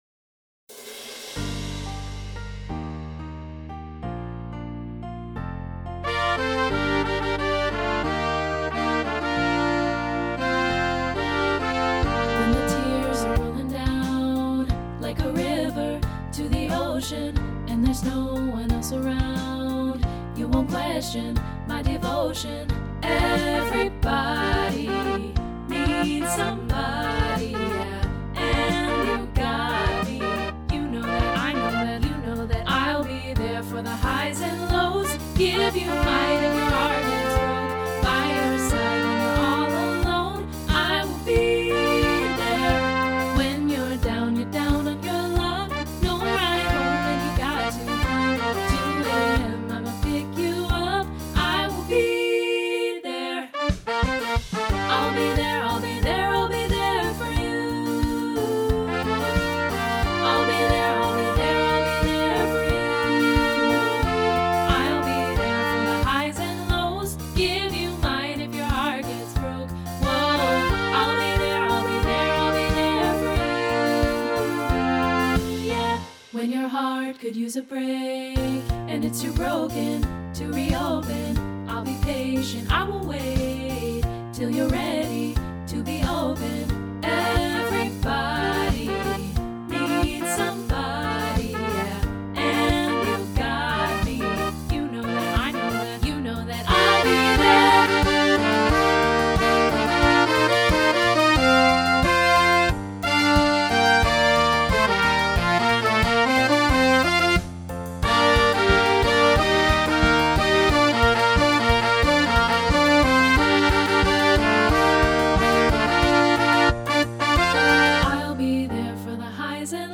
contemporary choral SSA arrangement
Check out the studio demo (MIDI instruments + live voices):
SSAA Pop Choral